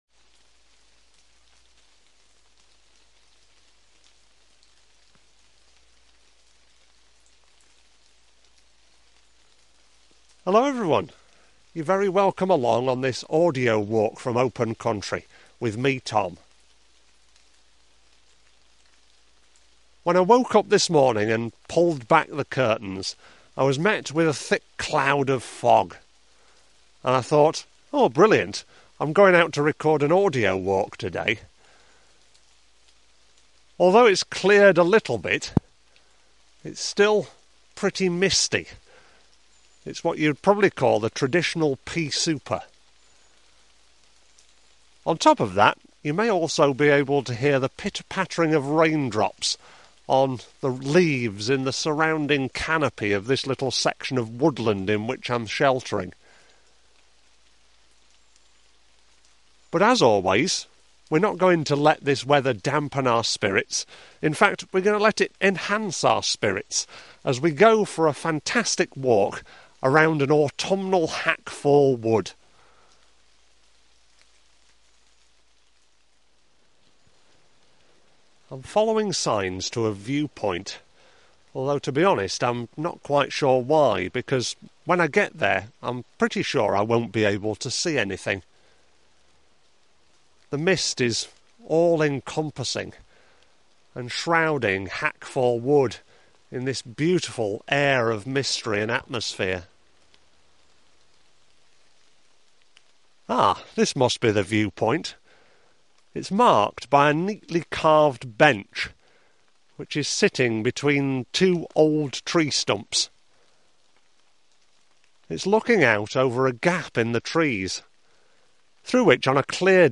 Hackfall Audio Walk
Take a journey into the quirky history of Hackfall Woods on an atmospheric, autumnal day.